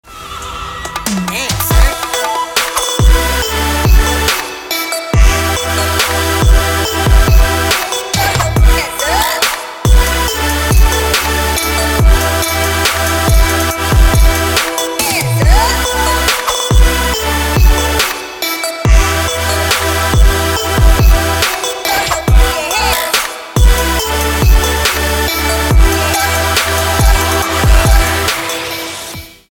Trap
Chill Trap
future trap